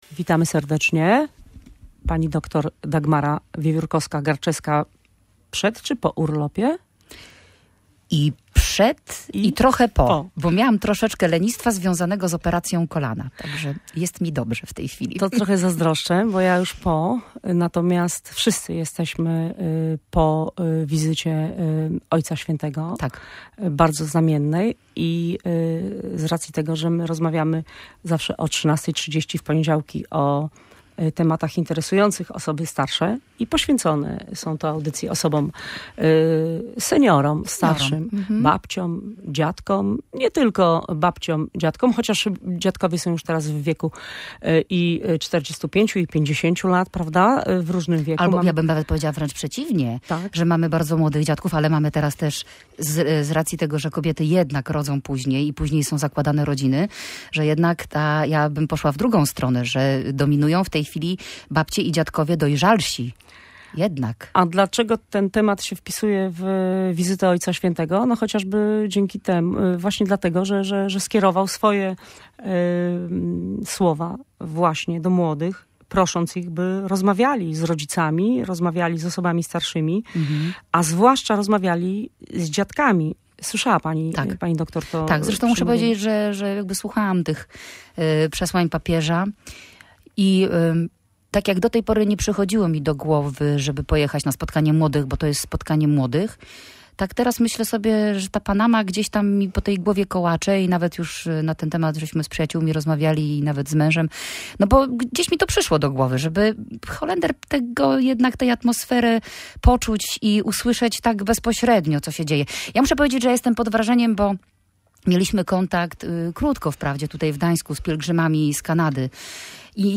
Rozmowa o starszych w kontekście papieskich słów. Co powiedział Papież do młodych o babciach i dziadkach?